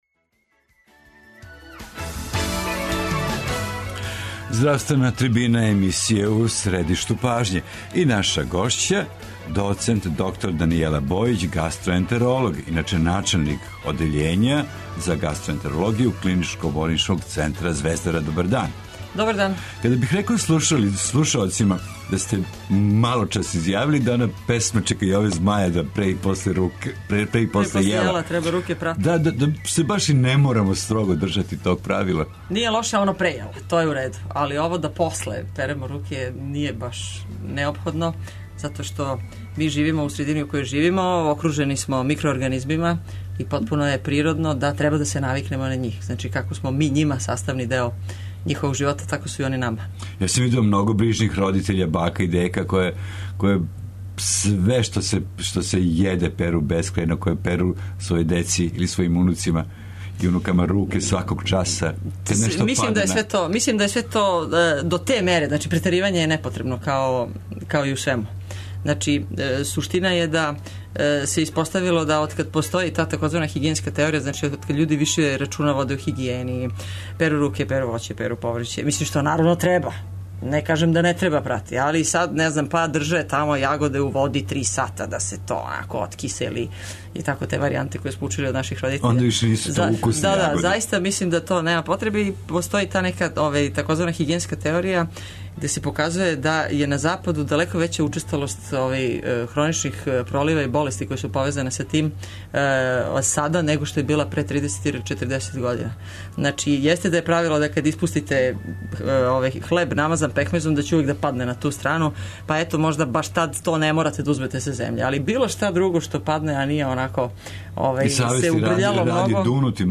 Kао и у свакој емисији, наш гост радо ће одговорити на ваша питања и дати вам савет.